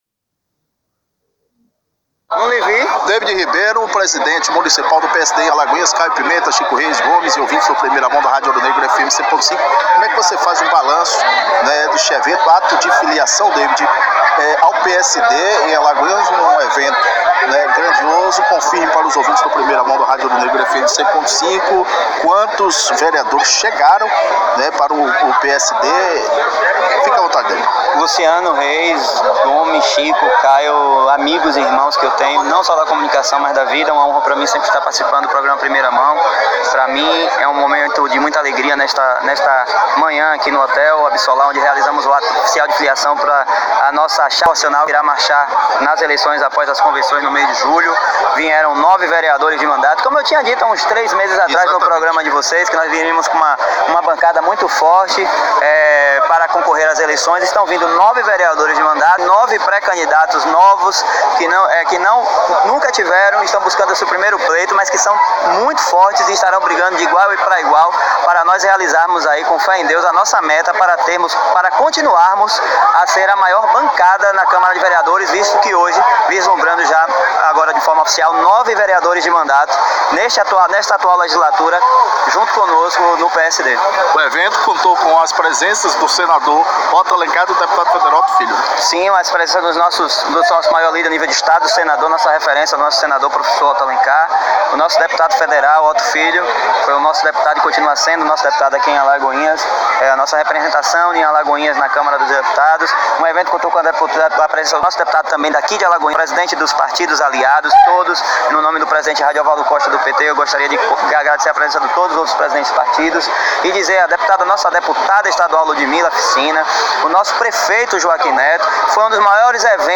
Ouça na integra, a entrevista do presidente municipal do PSD, na cidade de Alagoinhas (BA), David Ribeiro, no áudio abaixo: